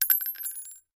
Bullet Shell Sounds
rifle_generic_1.ogg